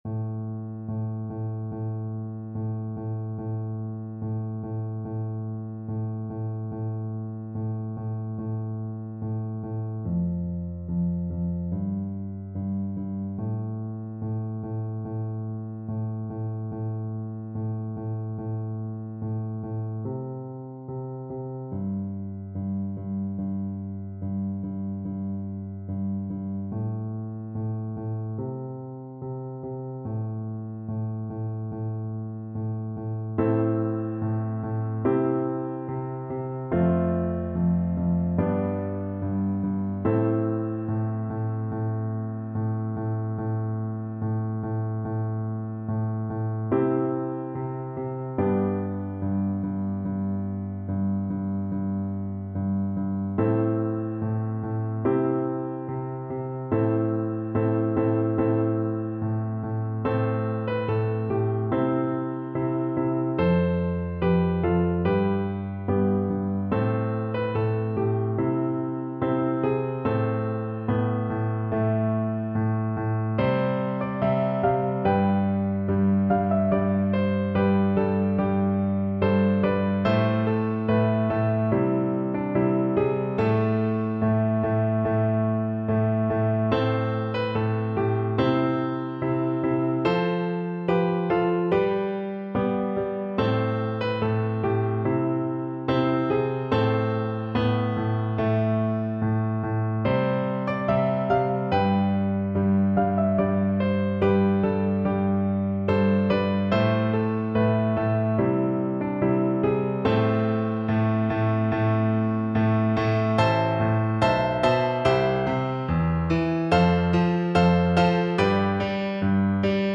Violin
Traditional Music of unknown author.
C major (Sounding Pitch) (View more C major Music for Violin )
Moderato =c.100
4/4 (View more 4/4 Music)
Classical (View more Classical Violin Music)